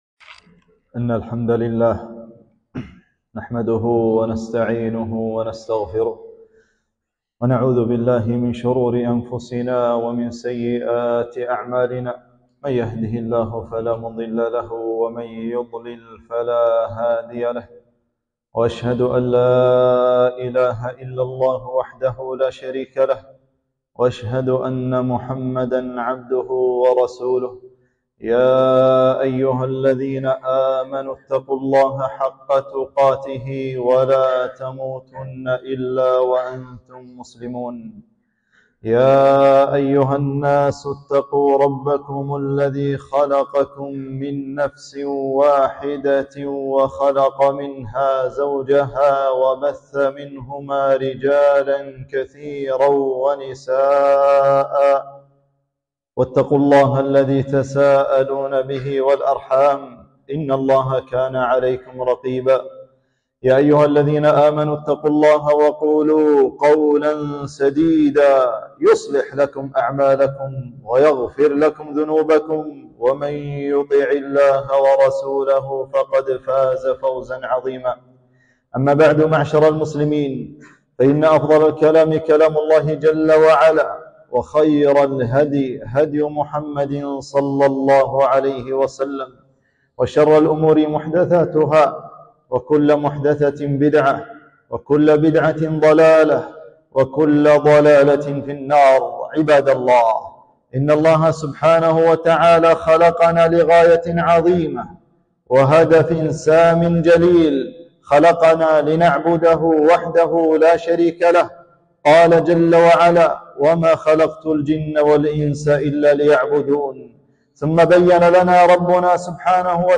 خطبة - فعل الخيرات وفضل شهر شعبان